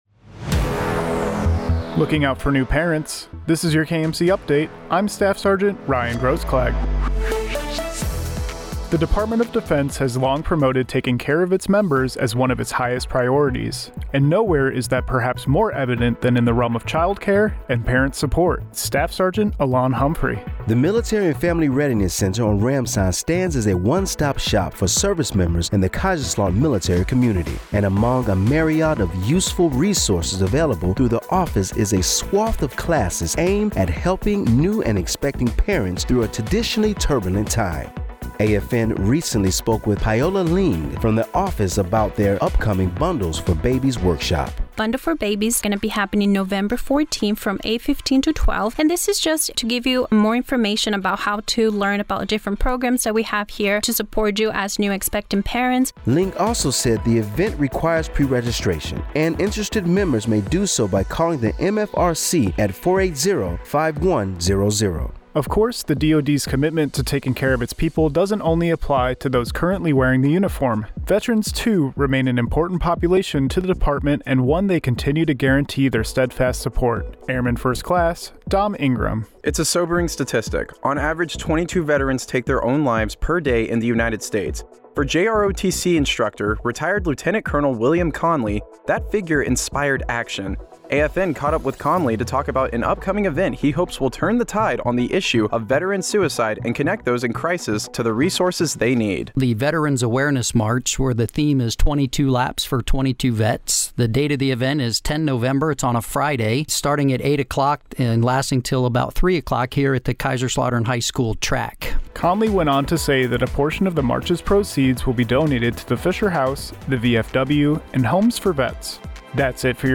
Representatives from the Military and Family Readiness Center on Ramstein Air Base, Germany, spoke with American Forces Network about an upcoming parent support class Oct. 27, 2023, supporting the Department of Defense priority of "taking care of people."